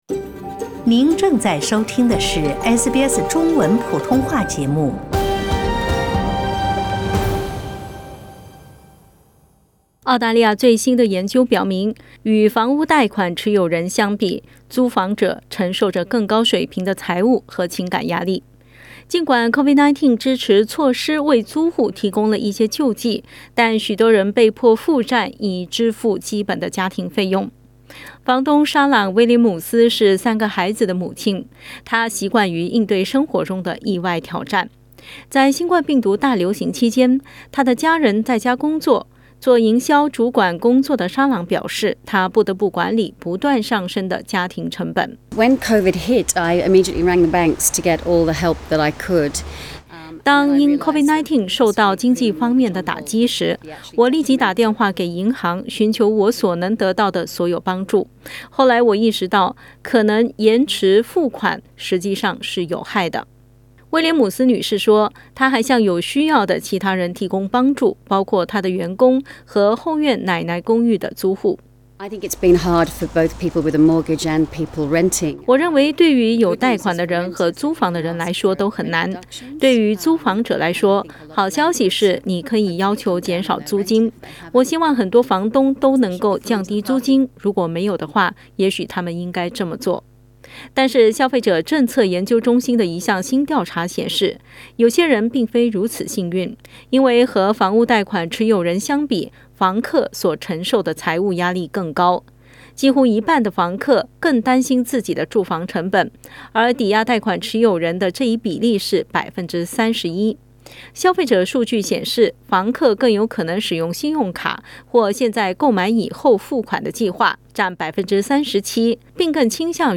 对房客的“六个月暂停驱逐令”已经到期。房客权益机构呼吁所有州和领地延长此期限。 点击图片收听详细报道。